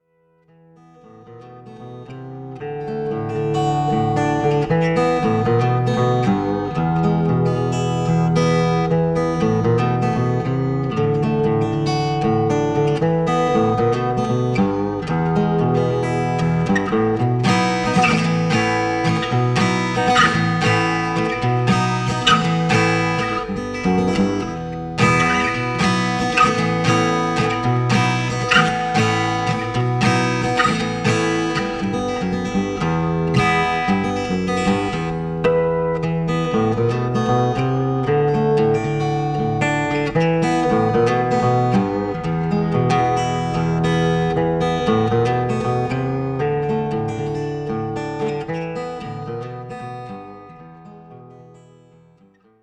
Sie wird mit Stahlsaiten bespannt und kann somit als Gitarrenmodell zwischen der Konzertgitarre und der E-Gitarre eingestuft werden. Sie kann sowohl mit den Fingern gezupft – dem so genannten Fingerpicking – als auch mit einem Plektrum geschlagen werden.